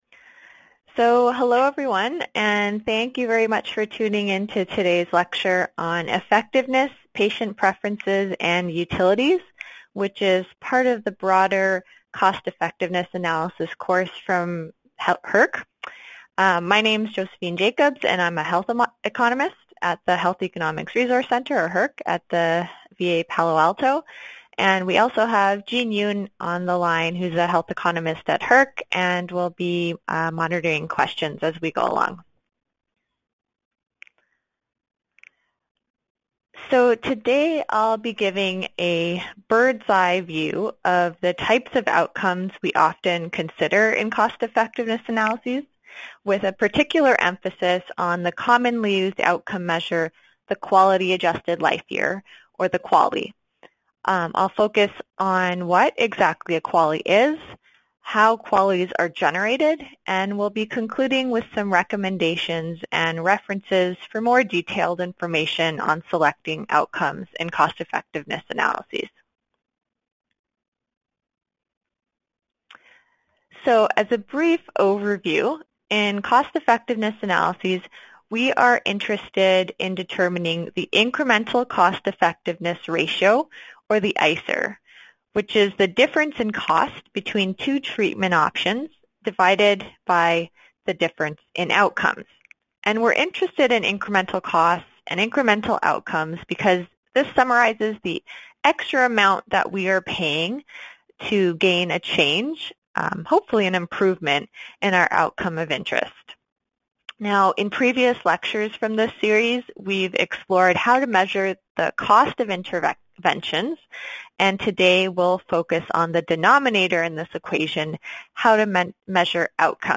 HERC Cost Effectiveness Analysis Seminar